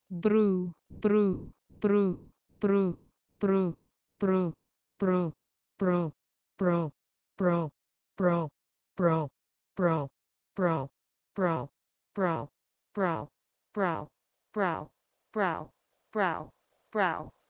E.g. 8. Likewise Old English brū --> Modern "brow":
bru-to-brow.wav